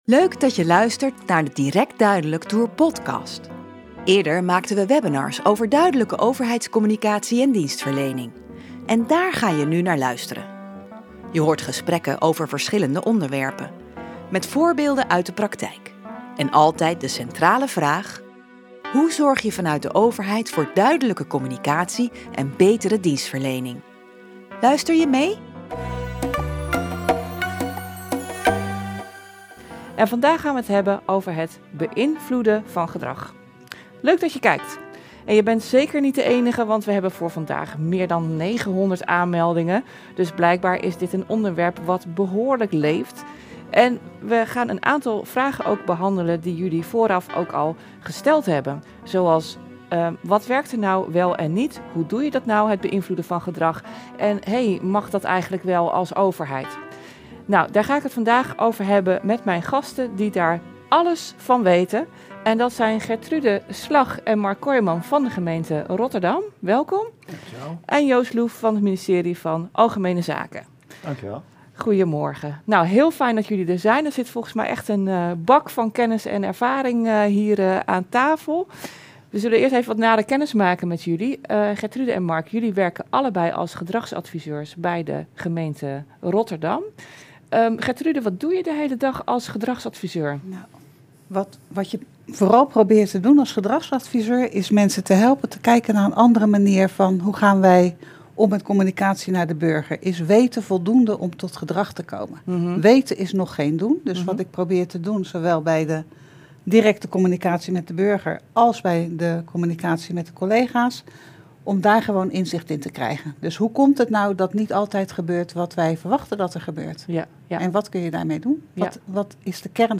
Je hoort gesprekken over verschillende onderwerpen.